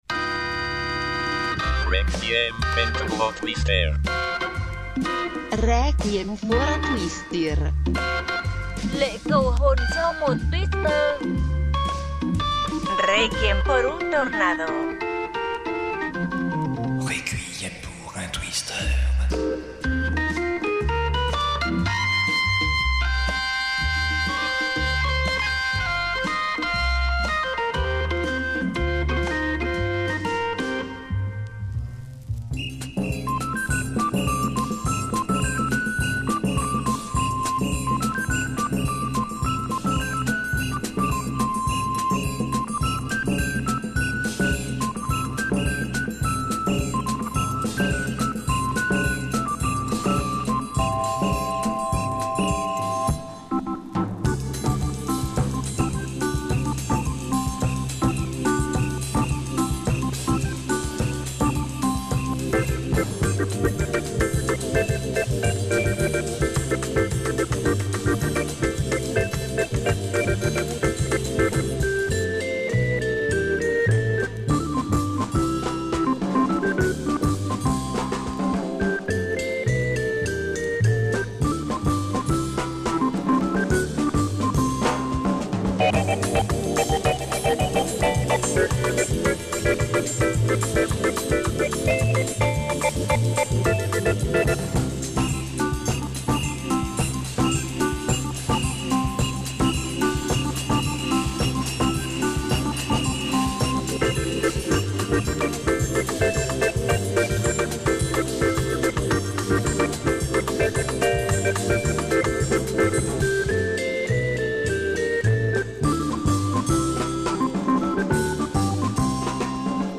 Quelques Oldies ... moins que d'habitude !
Type Mix Éclectique